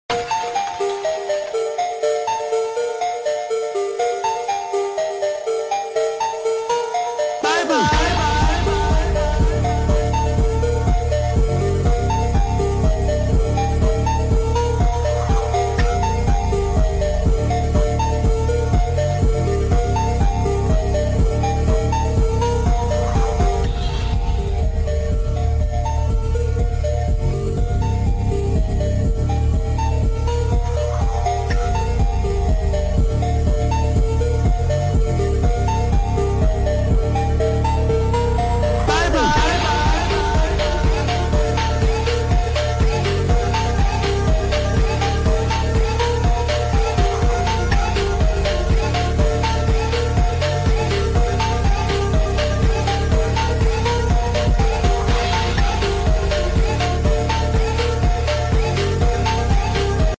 My cat mewing in 4k💀💀💀 sound effects free download